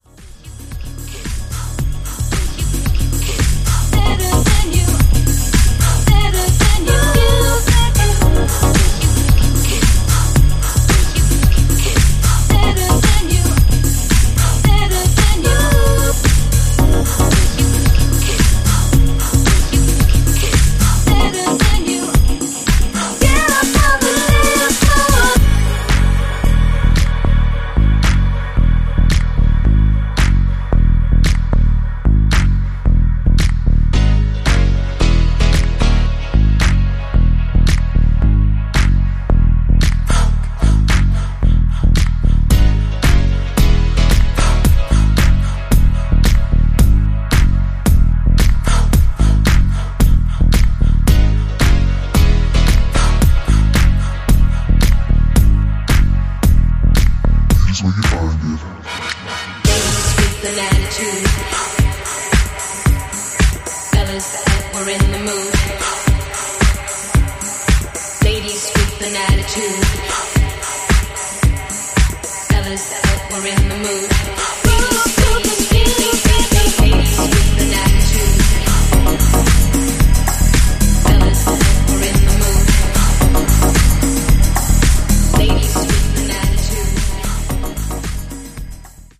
dubs out a classic house anthem